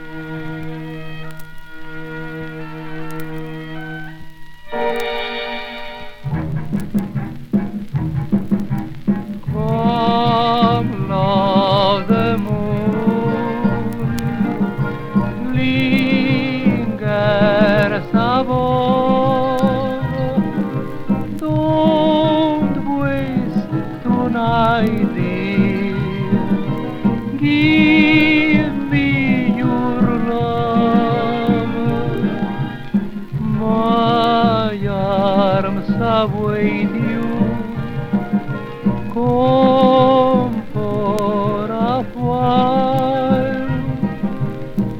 Pop, Vocal, Staege & Screen　UK　12inchレコード　33rpm　Mono